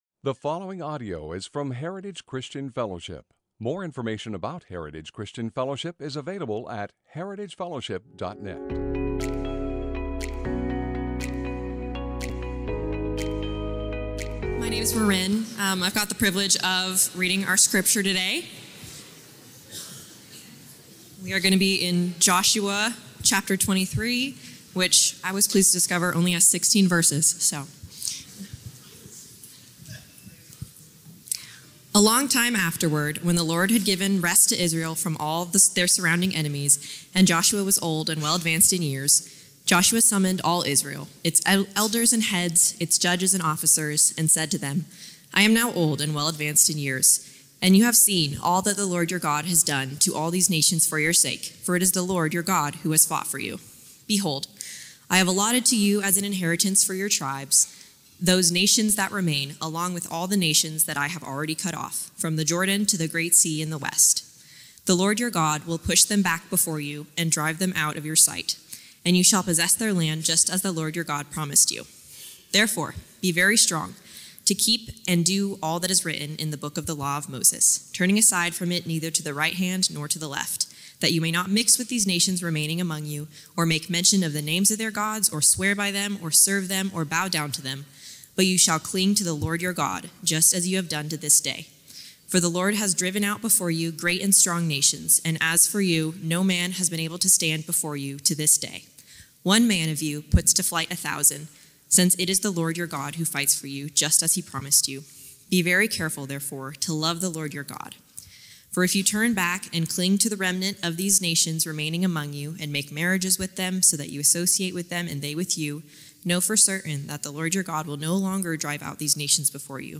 Sermons - Heritage Christian Fellowship | Of Medford, OR